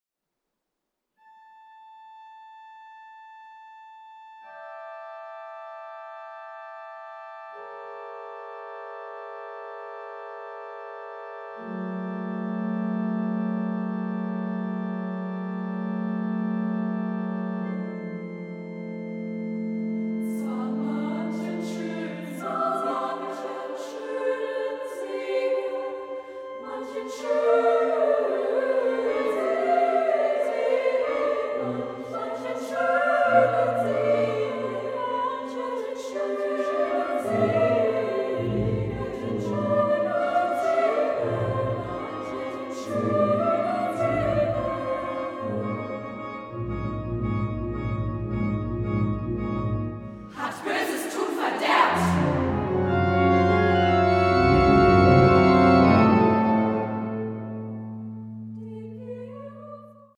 Werke für Frauen- und Kinderchor und Sololieder